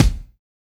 BEAT KICK 05.WAV